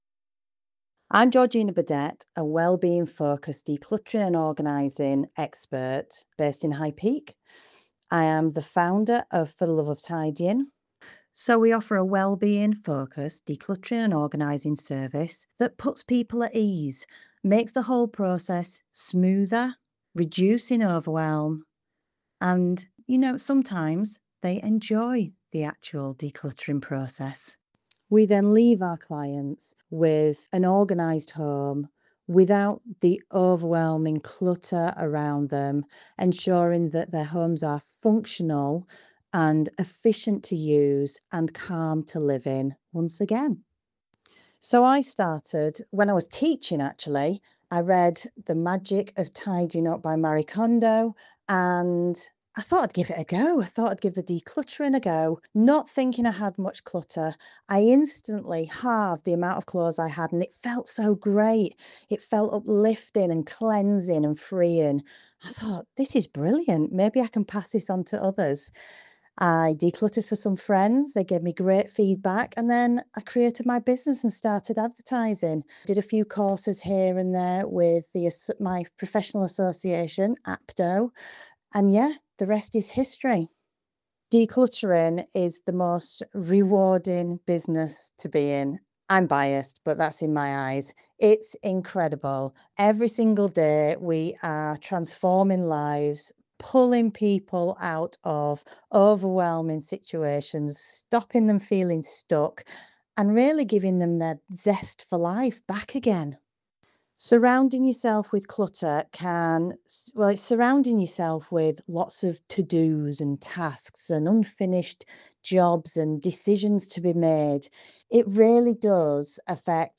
in the interview below.